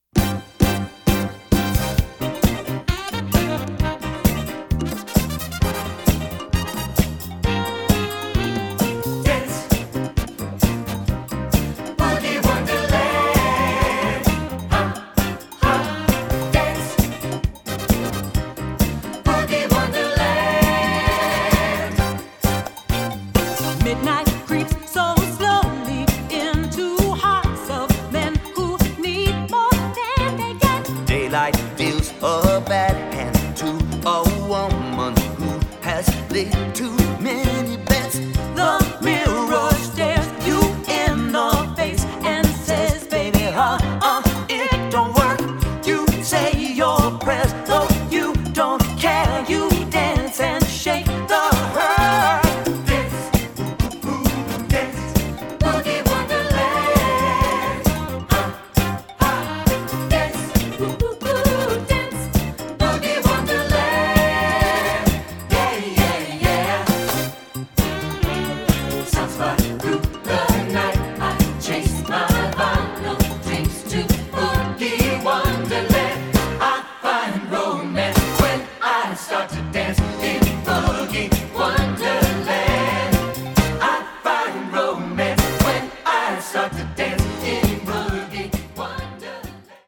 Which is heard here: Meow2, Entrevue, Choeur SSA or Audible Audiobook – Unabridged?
Choeur SSA